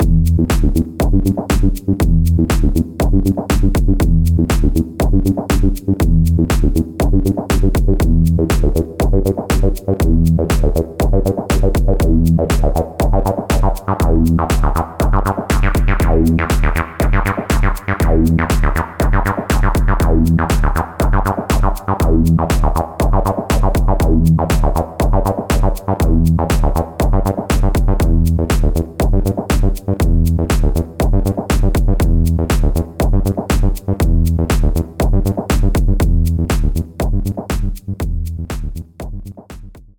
Grad eben auch mal getestet ob man mit Bordmitteln in Richtung TB-303 kommt. Basiert im Wesentlichen auf "Prism" mit entsprechenden Schrauben am Filter. Ist natürlich nicht 100% authentisch aber schon mal ganz ok für den Anfang.